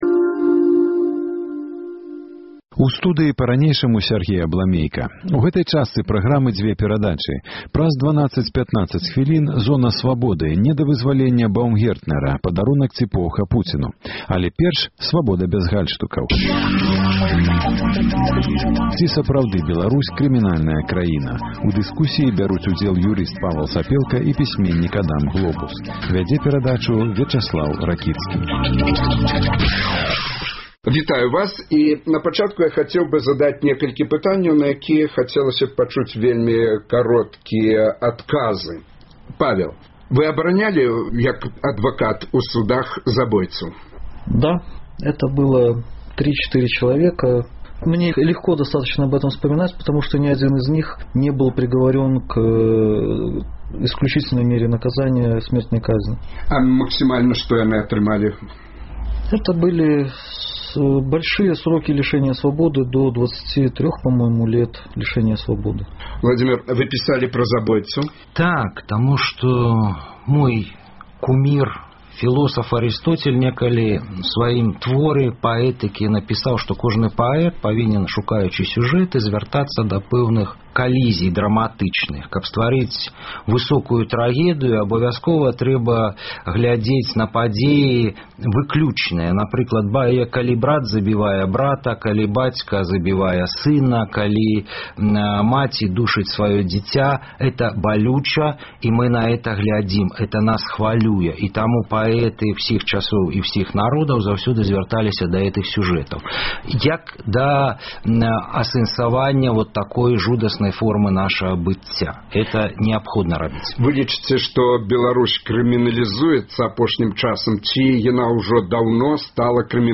Таксама свае меркаваньні выкажуць жыхары Менску.